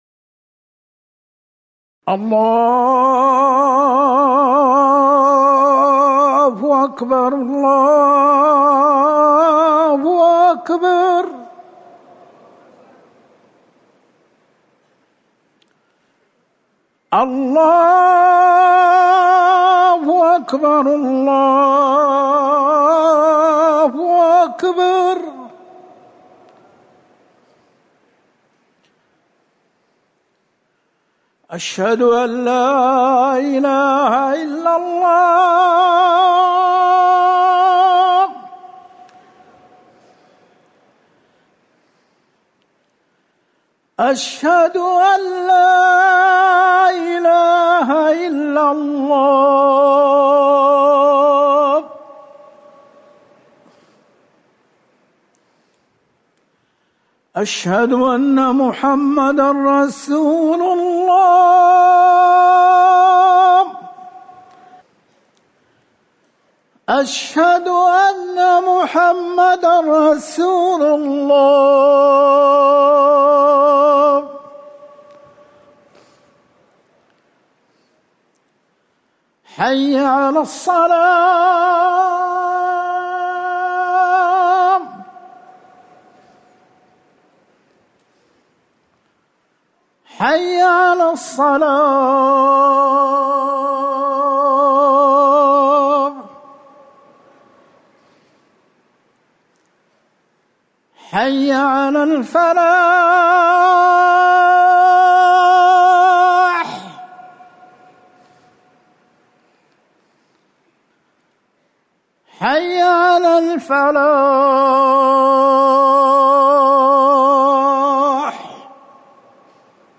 أذان الجمعة الأول
تاريخ النشر ٢٨ محرم ١٤٤١ هـ المكان: المسجد النبوي الشيخ